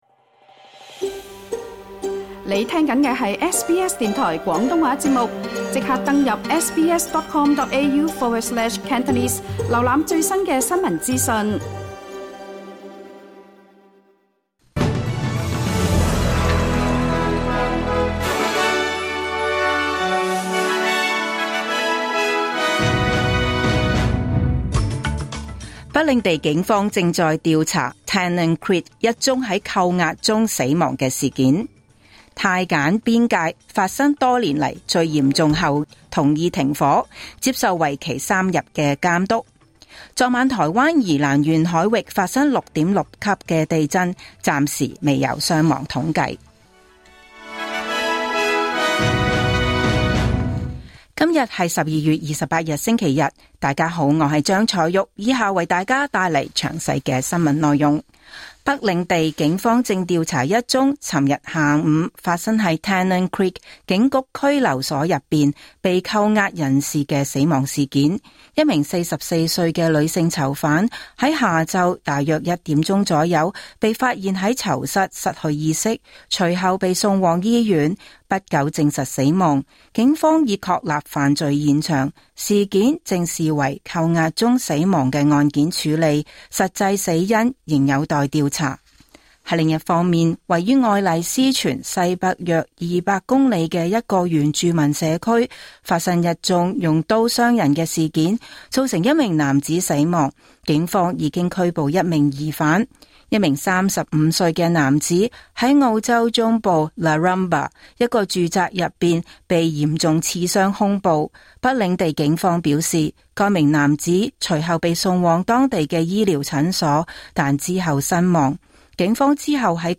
2025 年 12 月 28 日 SBS 廣東話節目詳盡早晨新聞報道。